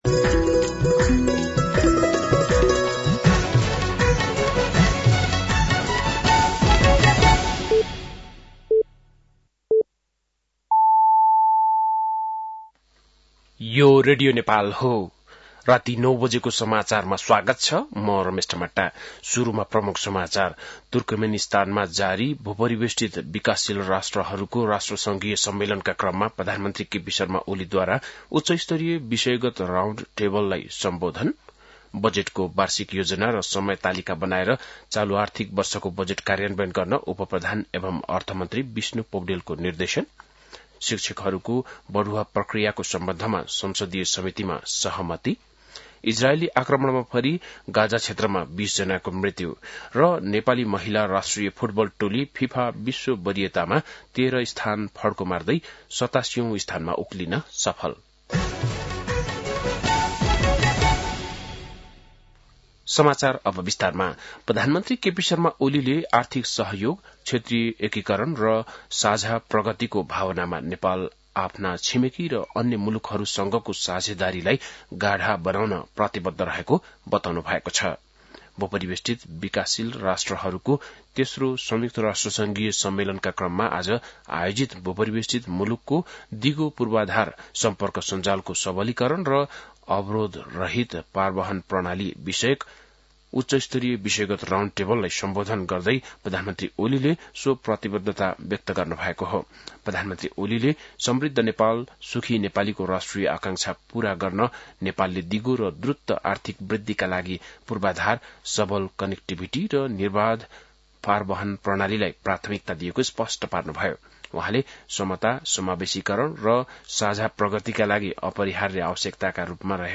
बेलुकी ९ बजेको नेपाली समाचार : २२ साउन , २०८२
9-PM-Nepali-NEWS-1-1.mp3